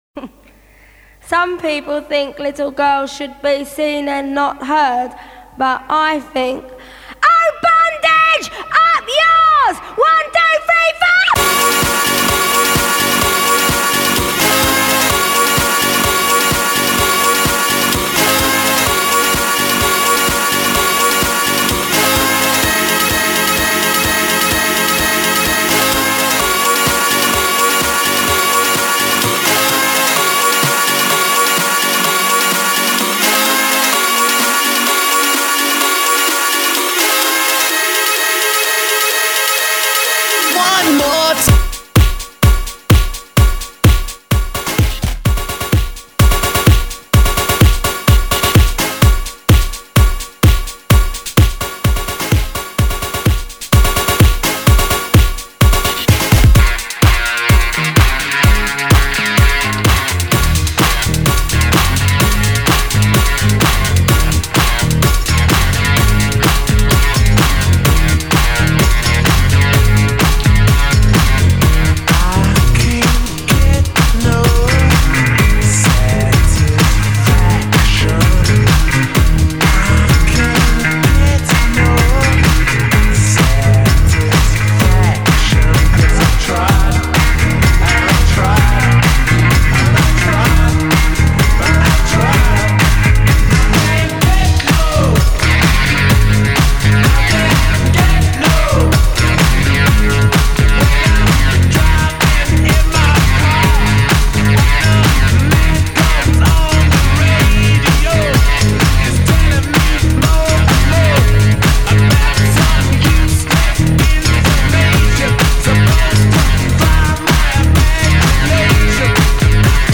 Please do not hot link this mix tape.